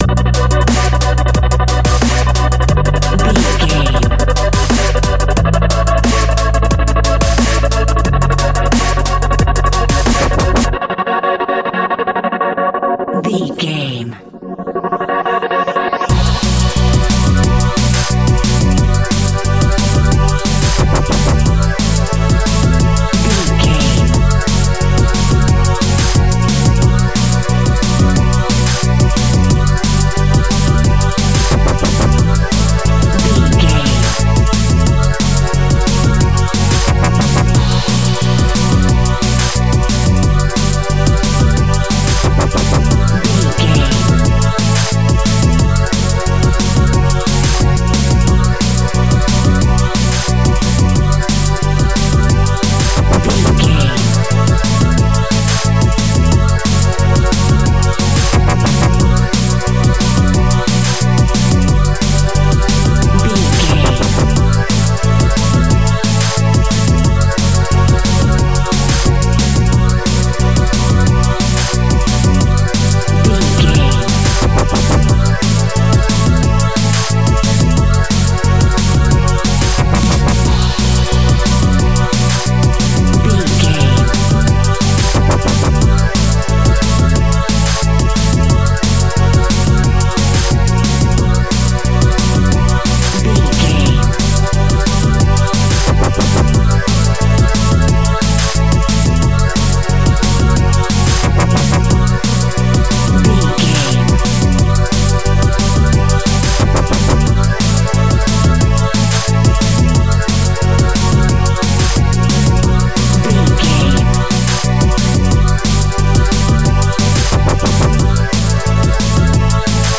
Fast paced
Aeolian/Minor
aggressive
dark
driving
energetic
drum machine
synthesiser
sub bass
synth leads